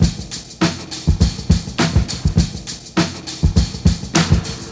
• 102 Bpm Drum Loop C Key.wav
Free drum beat - kick tuned to the C note. Loudest frequency: 1779Hz
102-bpm-drum-loop-c-key-oZF.wav